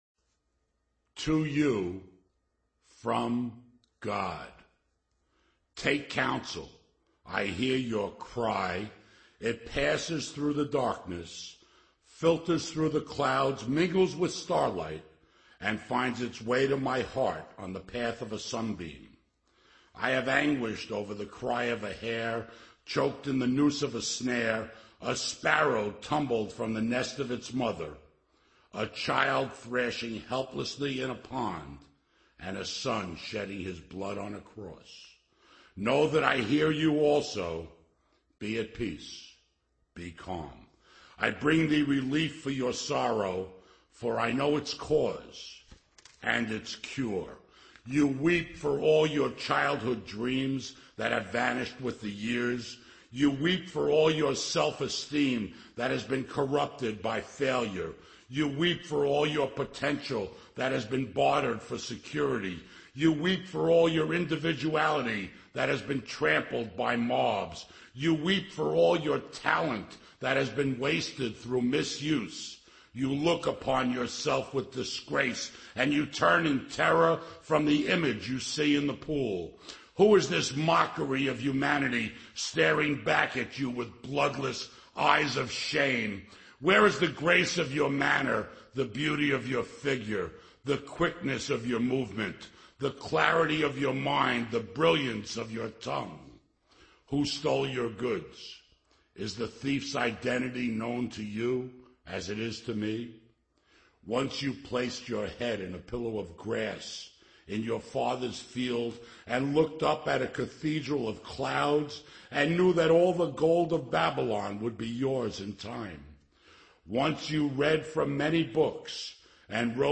Alcoholics Anonymous Speaker Recordings